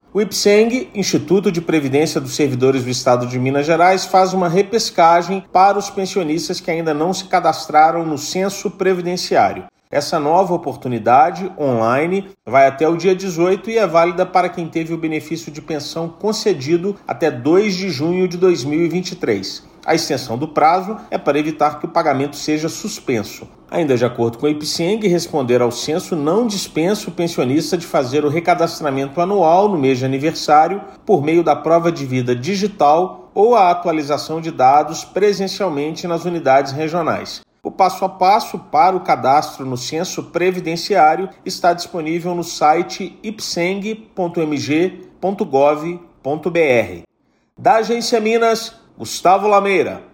Essa nova oportunidade começou em 18/8 e vai até 18/9 e é válida para os pensionistas que tiveram o benefício de pensão concedido até 2/6/2023. Ouça matéria de rádio.